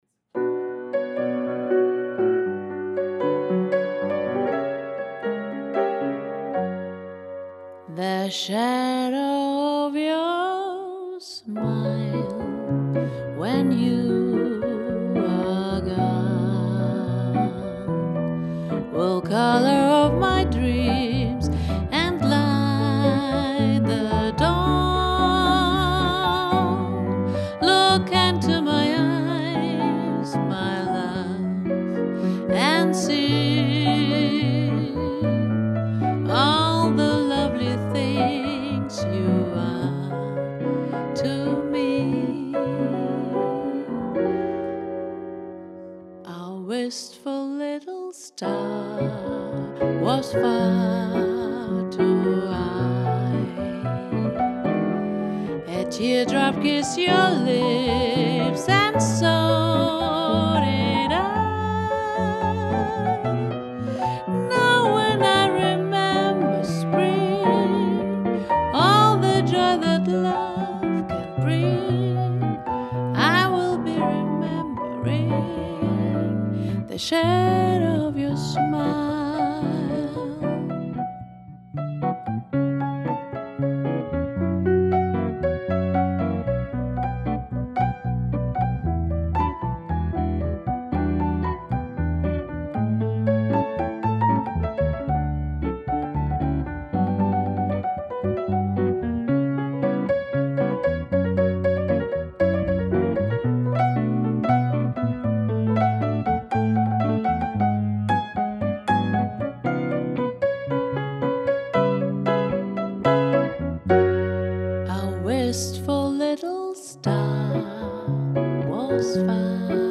Vocal Jazz Live